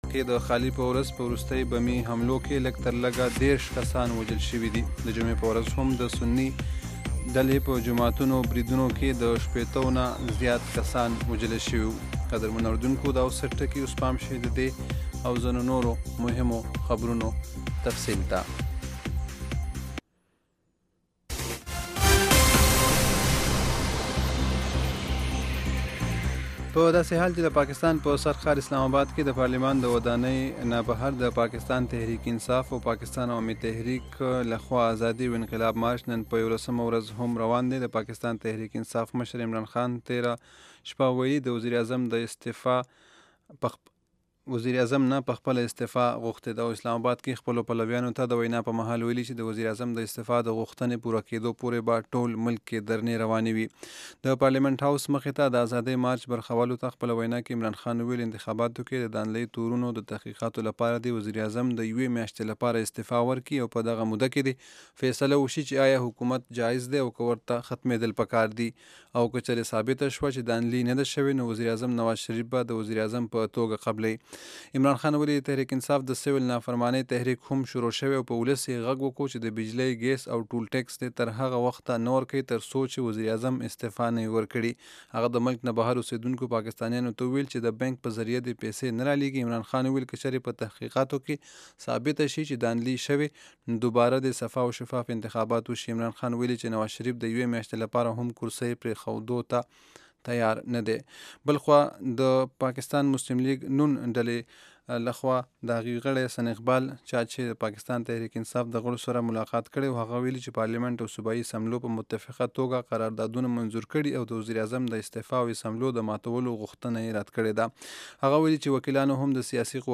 خبرونه - 0330